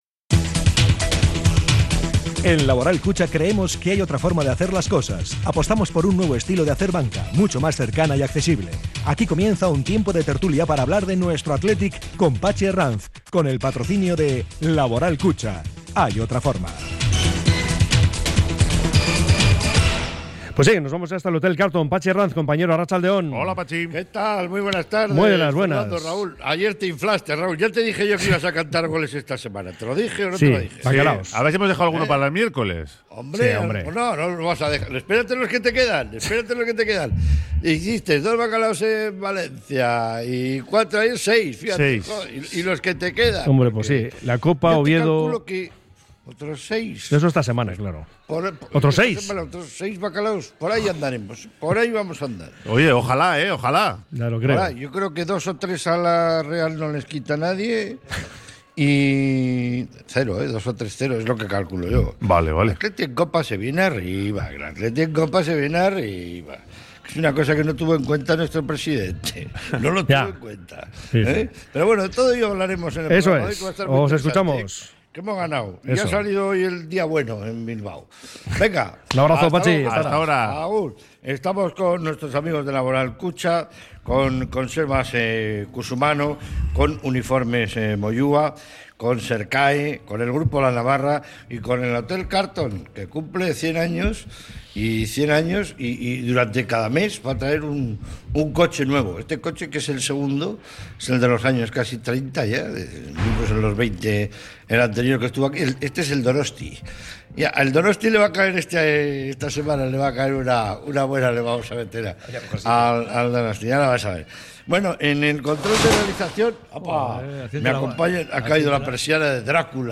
desde el Hotel Carlton